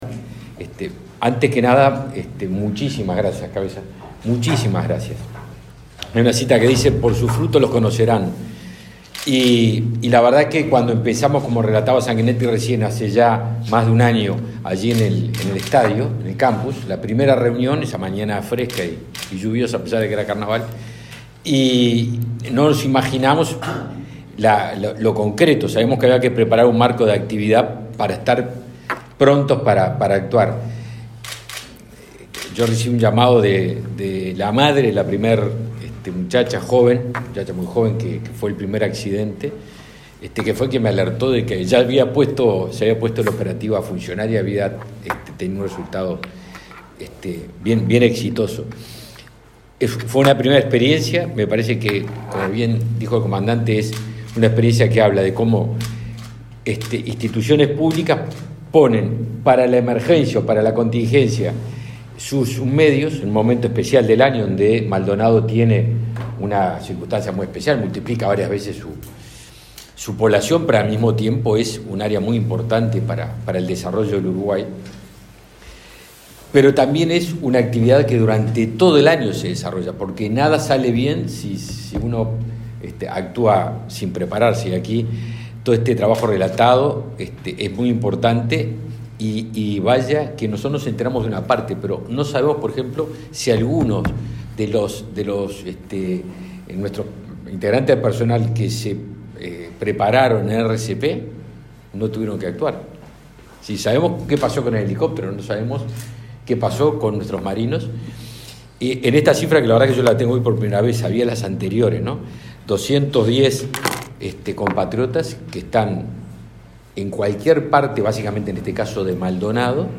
Palabras del ministro de Defensa Nacional, Javier García
El ministro de Defensa Nacional, Javier García, participó, este lunes 6 en Maldonado, en una jornada de evaluación de la aplicación del convenio sobre